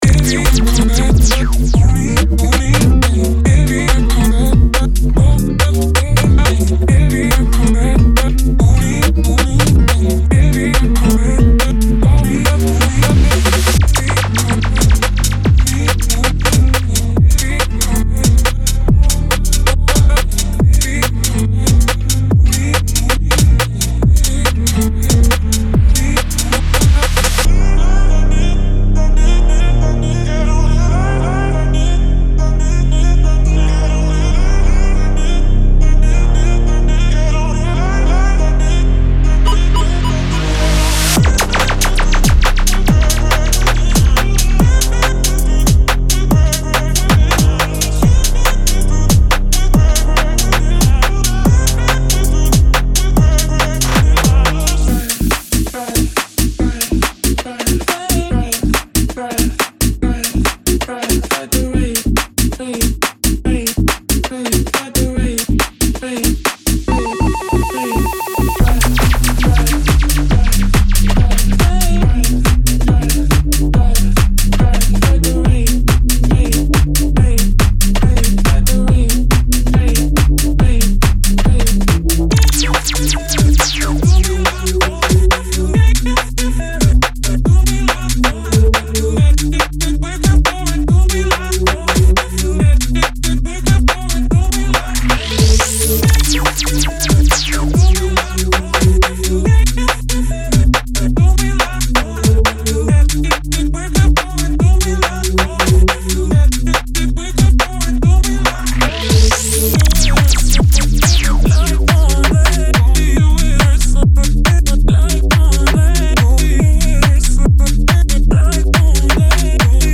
Genre:Garage
137〜141 BPMのループを収録しています。
デモサウンドはコチラ↓